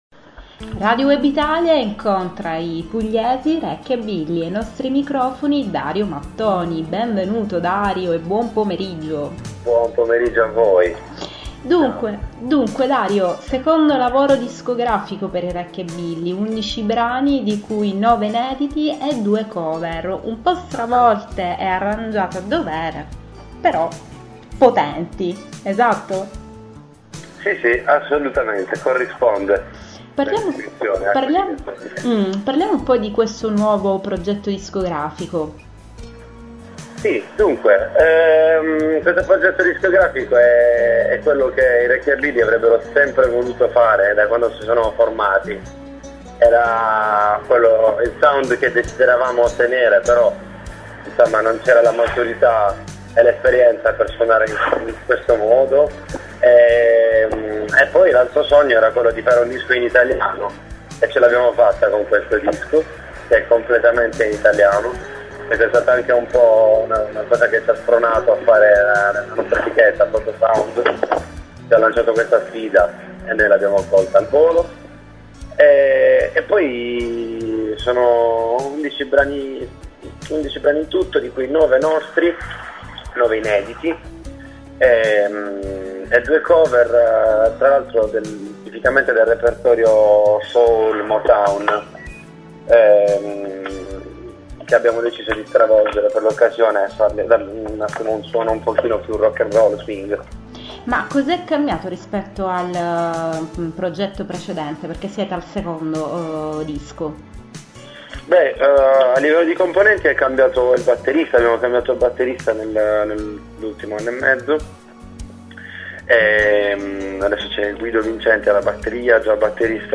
Rekkiabilly ai microfoni di Radio Web Italia presentano il secondo lavoro discografico, prodotto dalla Protosound Polyproject e VOLUME! Records.
rekkiabili-intervista-06.mp3